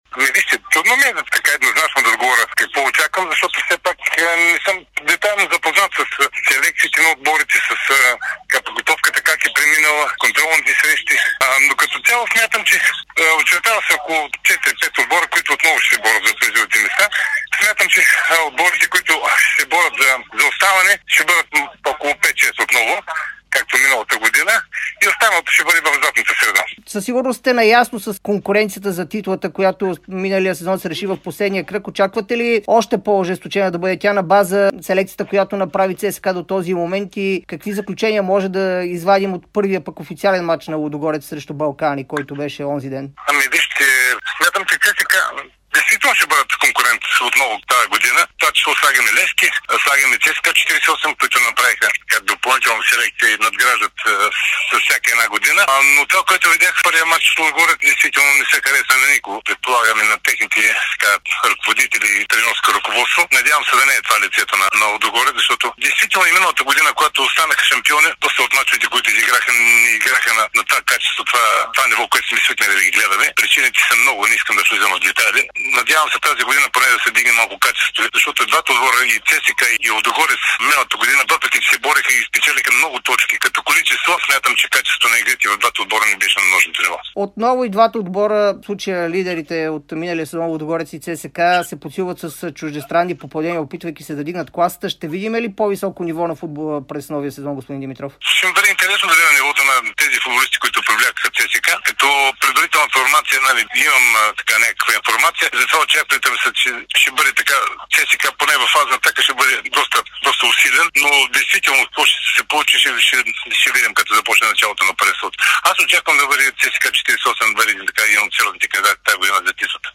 Опитният специалист Димитър Димитров – Херо говори специално пред Дарик радио и dsport.